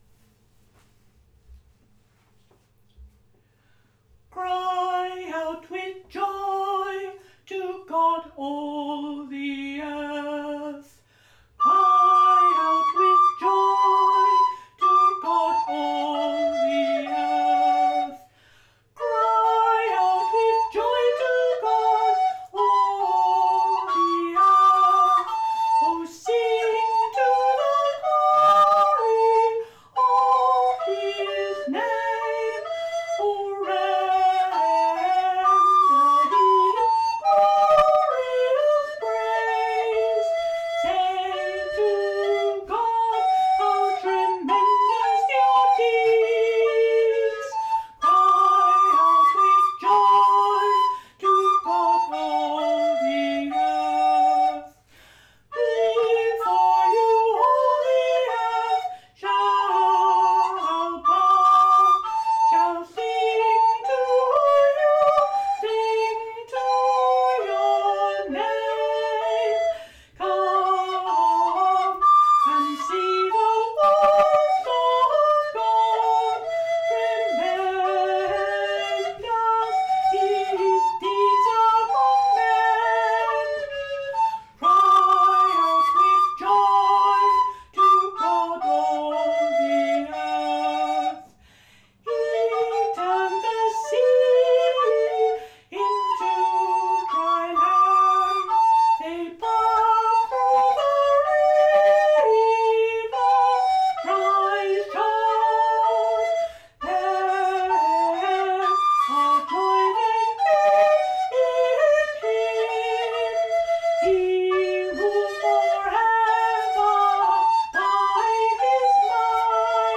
playing the recorder